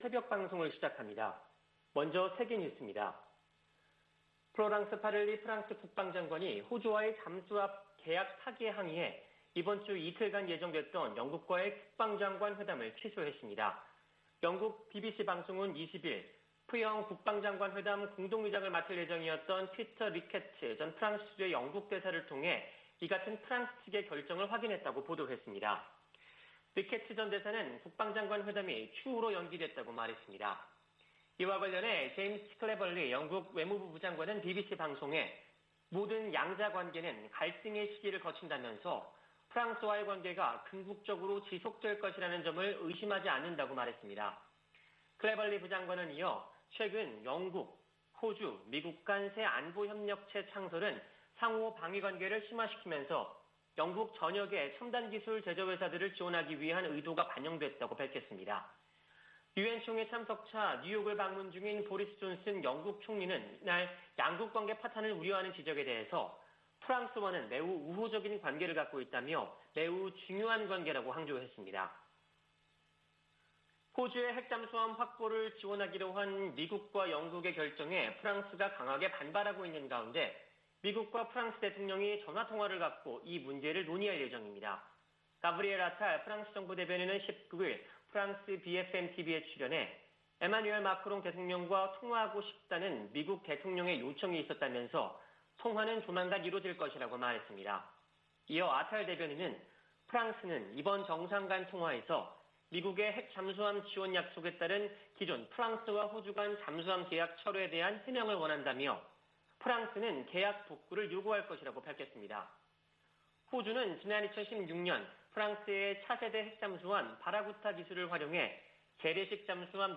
VOA 한국어 '출발 뉴스 쇼', 2021년 9월 21일 방송입니다. 유엔주재 미국 대사가 북한의 최근 미사일 발사 관련 사안을 대북제재위원회에서도 논의할 것이라고 밝혔습니다. 존 하이튼 미 합참의장은 북한이 미사일 역량을 빠른 속도로 발전시켰다고 17일 말했습니다. 열차를 이용한 북한의 탄도미사일 시험발사는 선제적 대응을 어렵게할 것이라고 미국의 군사 전문가들이 분석했습니다.